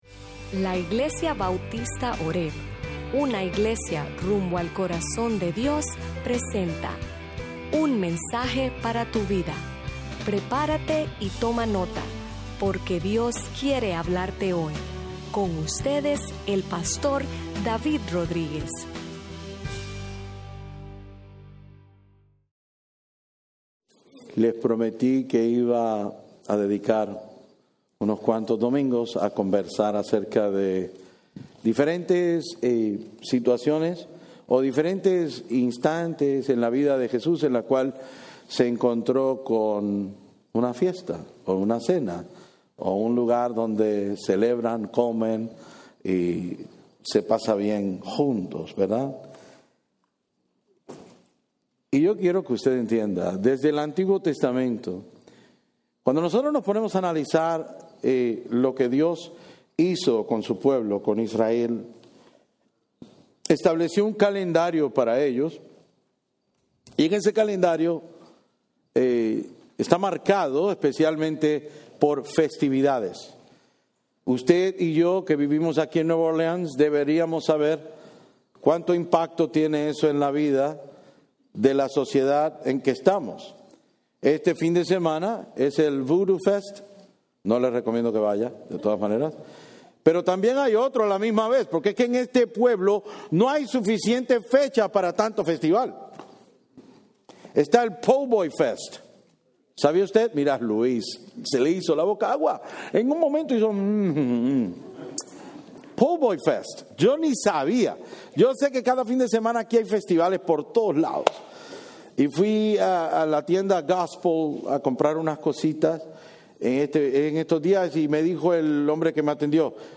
Sermons Archive - Page 90 of 154 - horebnola-New Orleans, LA